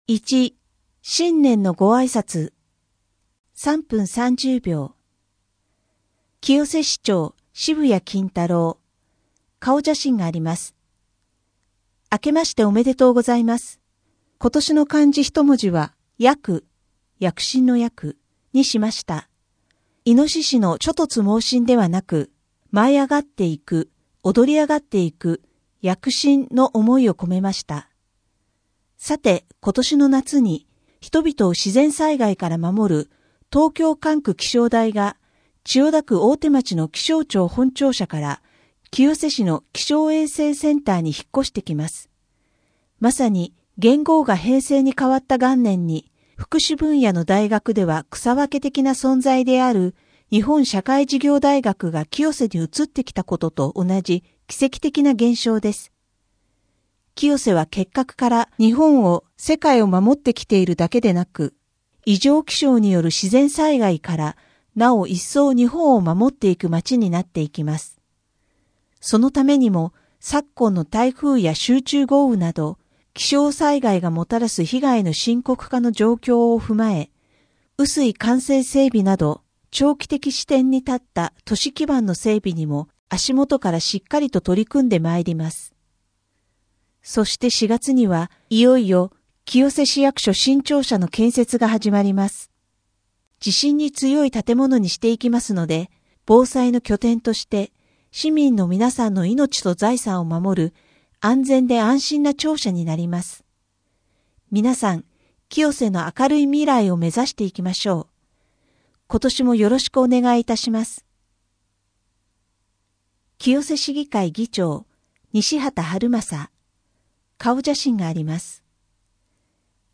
声の広報は清瀬市公共刊行物音訳機関が制作しています。